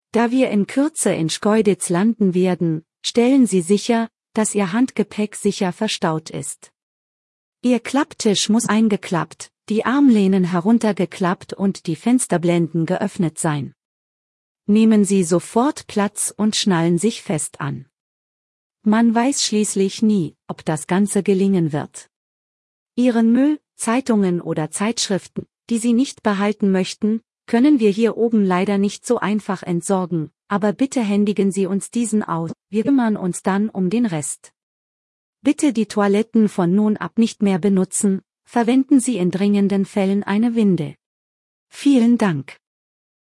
DescentSeatbelts.ogg